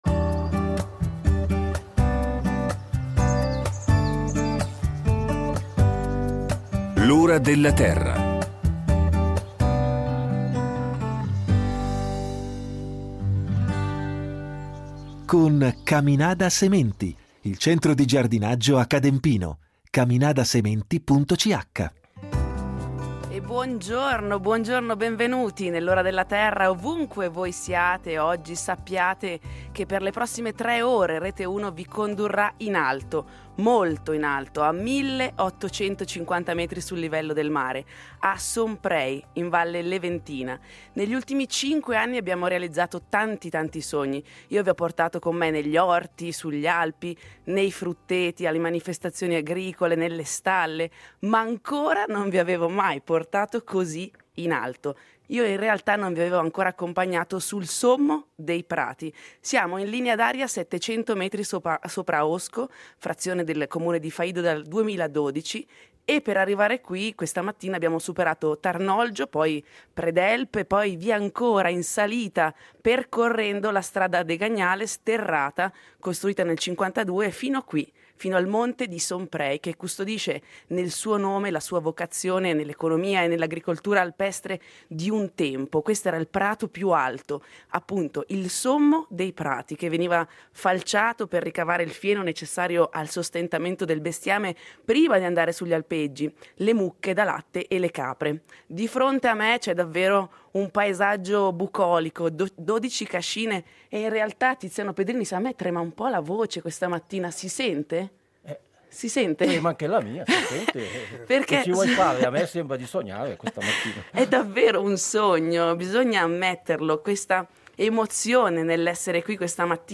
sarà in diretta dal monte di Somprei , sopra a Osco , frazione del comune di Faido nella media Leventina.